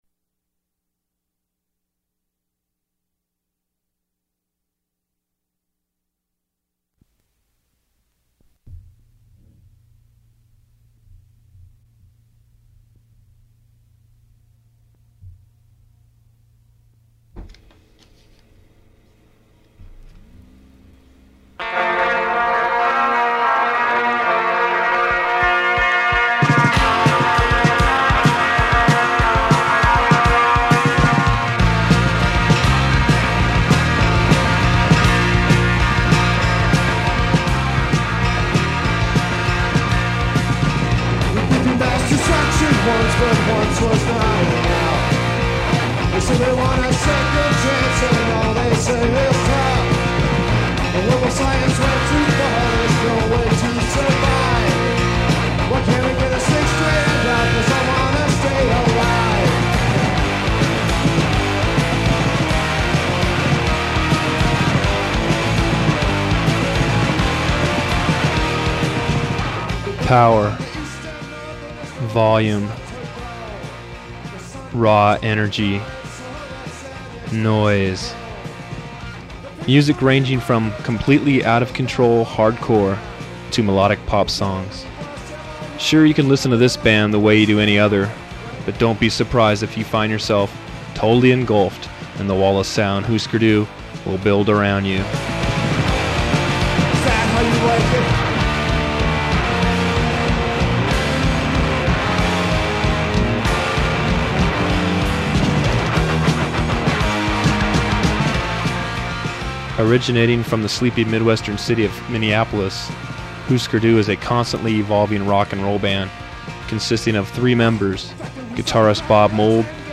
Interview
Songs and fragments of songs are interspersed with the interview content.
Phone interview with Grant Hart, drummer and singer, begins
Form of original Open reel audiotape